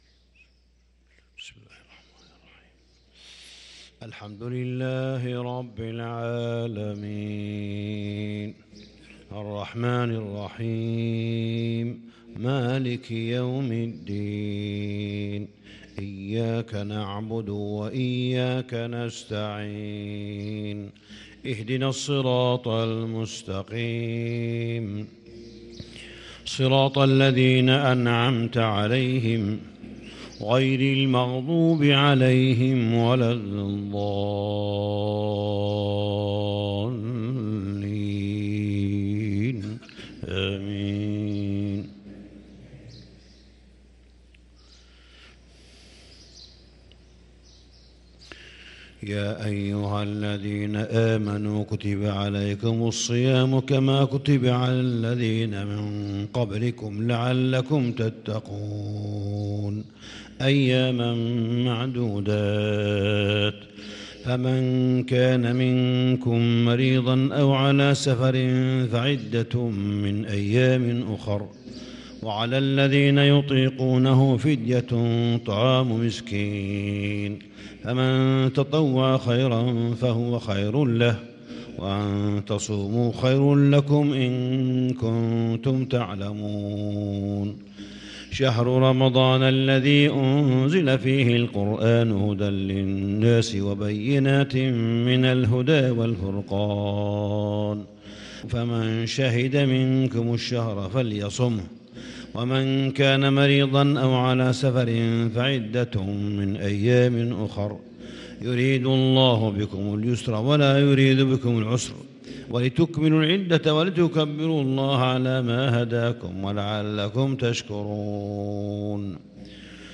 صلاة الفجر للقارئ صالح بن حميد 1 رمضان 1443 هـ
تِلَاوَات الْحَرَمَيْن .